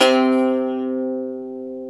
ETH XSAZ  C4.wav